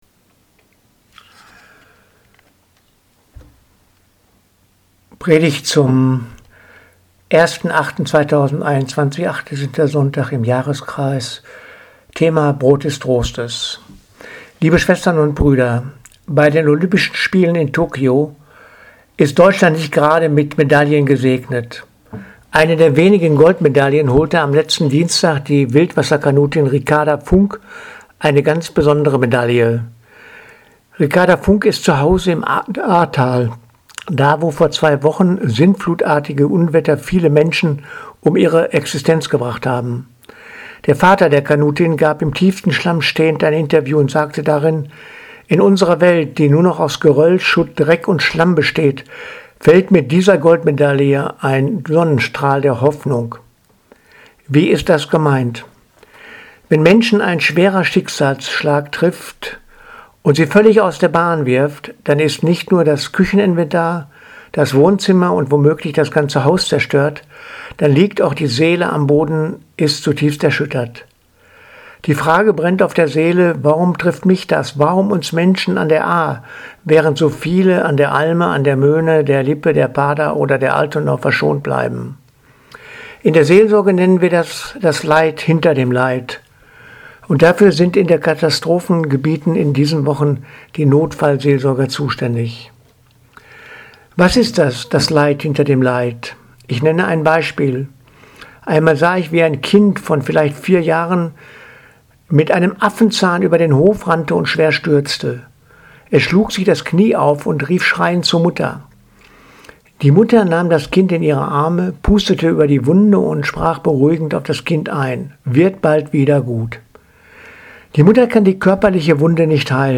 Predigt vom 1.8.2021 – Brot des Trostes